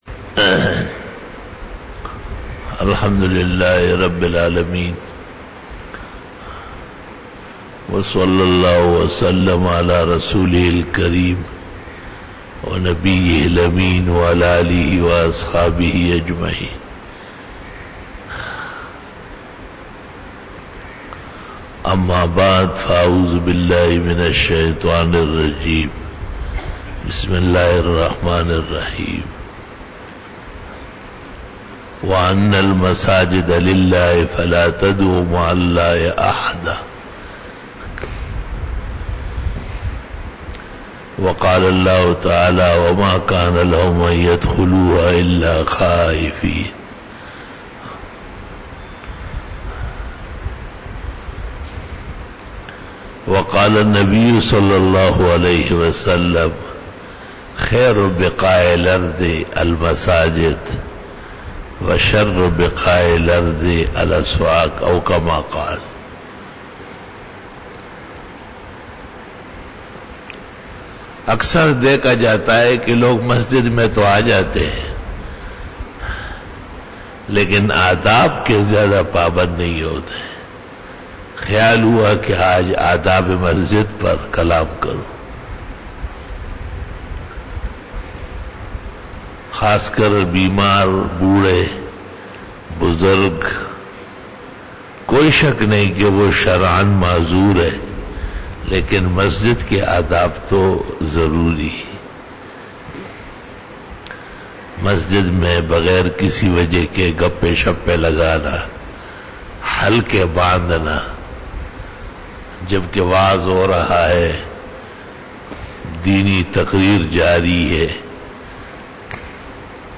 40 BAYAN E JUMA TUL MUBARAK (04 October 2019) (04 Safar 1441H)
Khitab-e-Jummah 2019